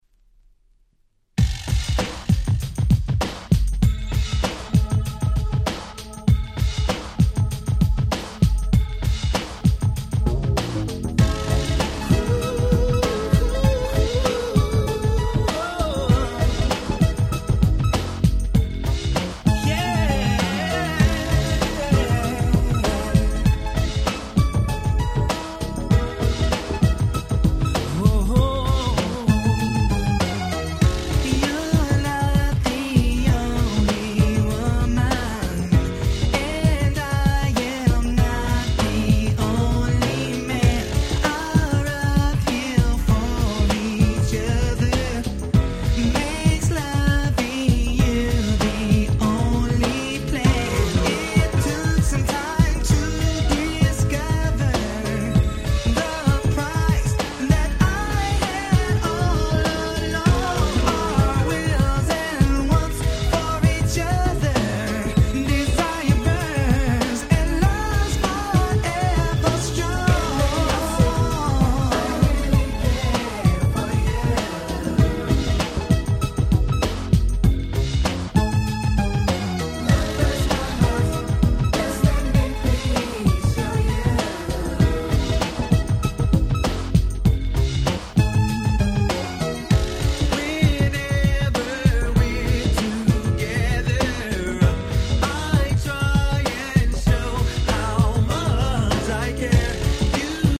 ですので音圧バッチリなこちらのWhite盤をPlay用にオススメいたします！